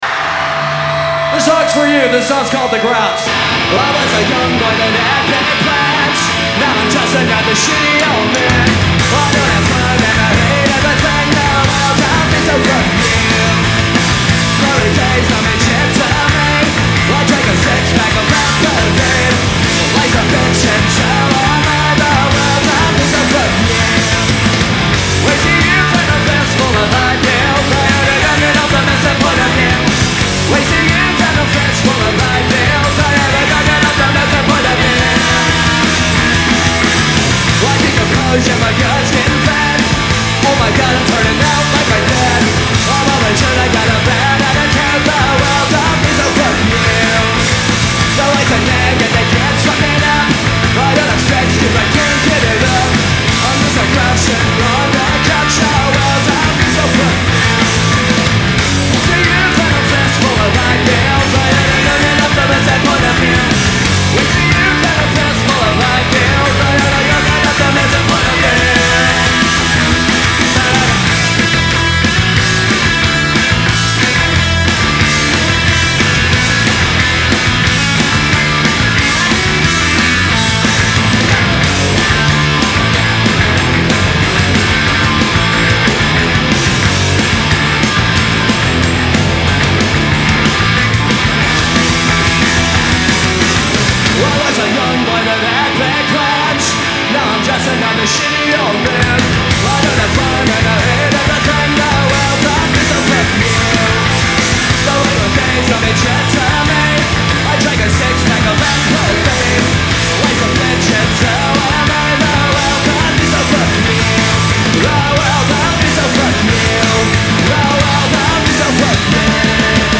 Live at the Electric Factory, Philadelphia 11/14/97